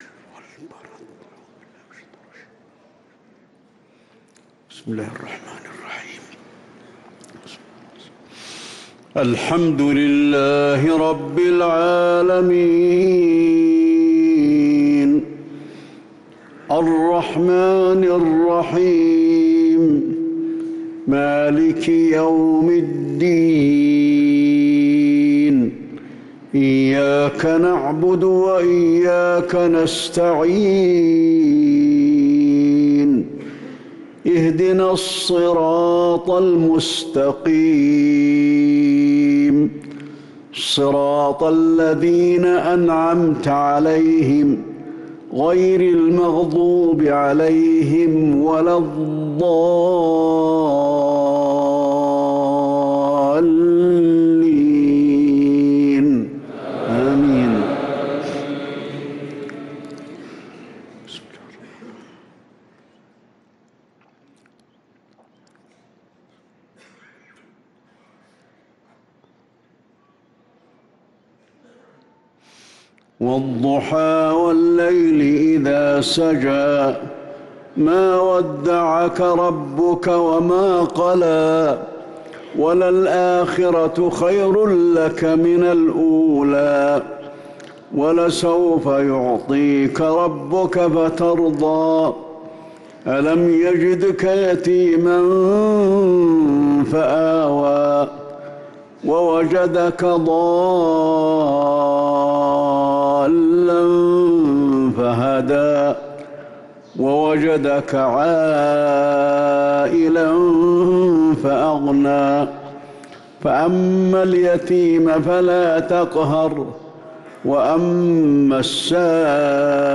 صلاة المغرب للقارئ علي الحذيفي 23 رمضان 1444 هـ